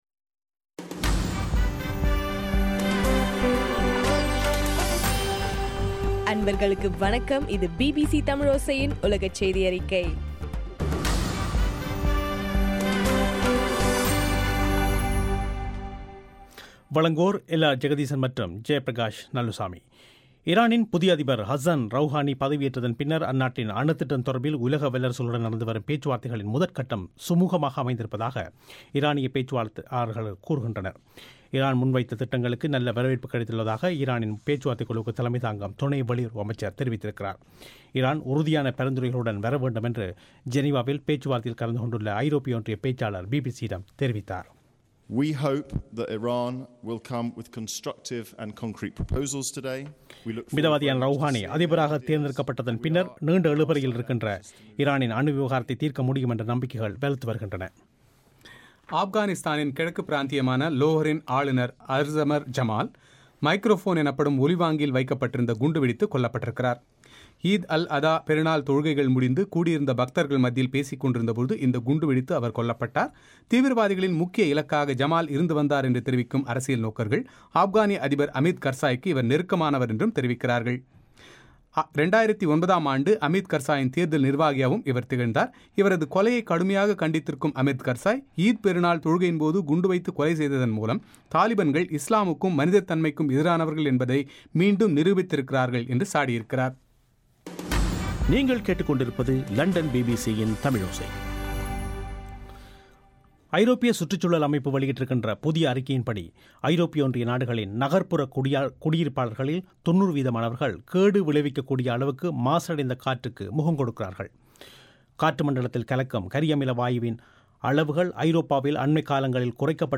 இலங்கையின் சக்தி எப்எம் வானொலியில் ஒலிபரப்பான பிபிசி தமிழோசையின் உலகச் செய்தியறிக்கை